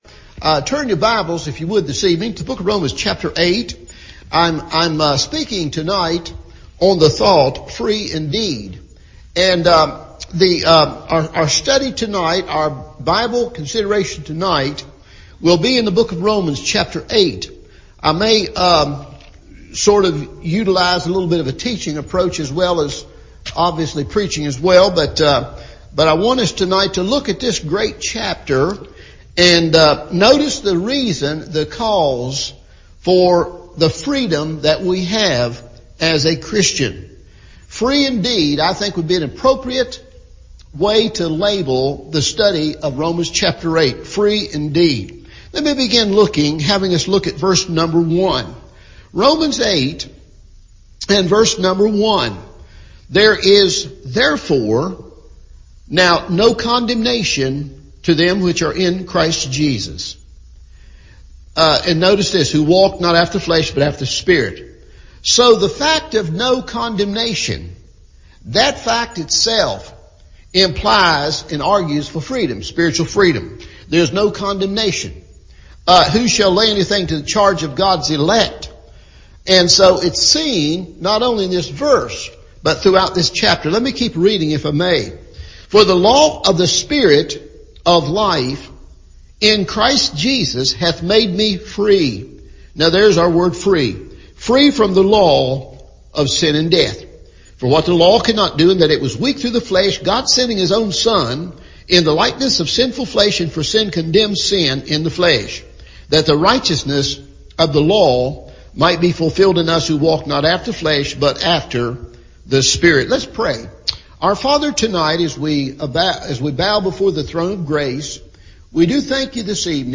Free Indeed – Evening Service